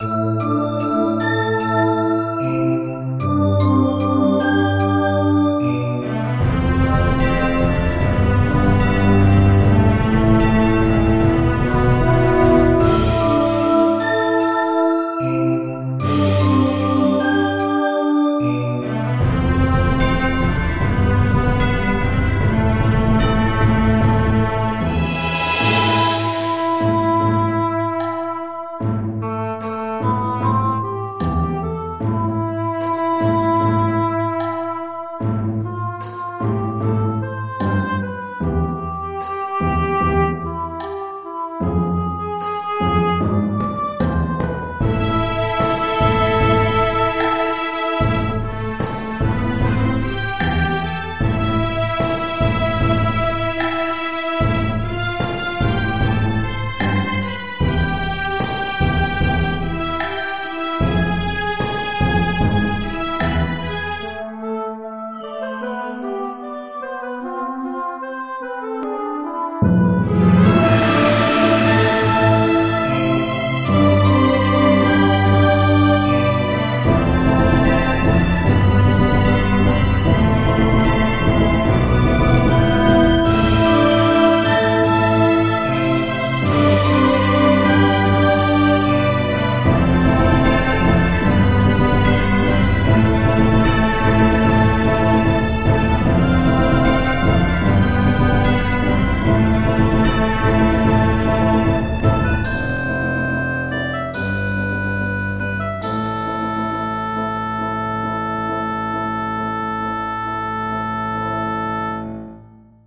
その心情をイングリッシュホルンが奏でます。